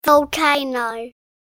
دانلود آهنگ آتشفشان 1 از افکت صوتی طبیعت و محیط
دانلود صدای آتشفشان 1 از ساعد نیوز با لینک مستقیم و کیفیت بالا
جلوه های صوتی